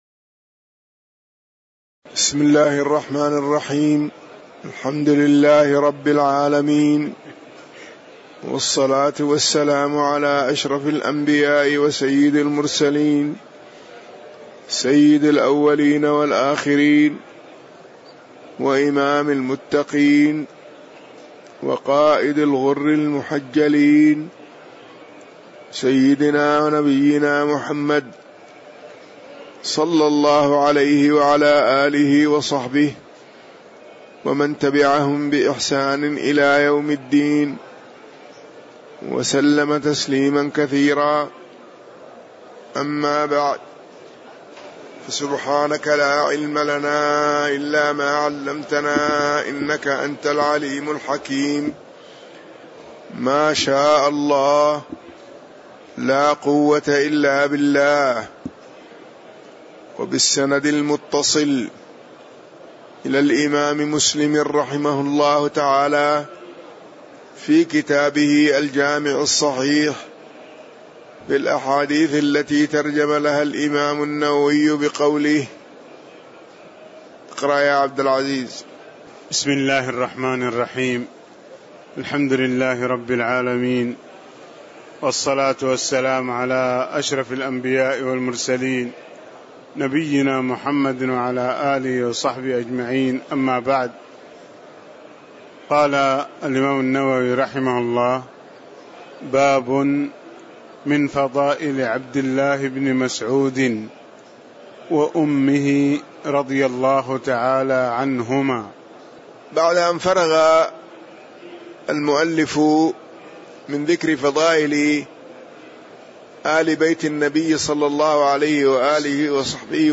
تاريخ النشر ١٥ رمضان ١٤٣٧ هـ المكان: المسجد النبوي الشيخ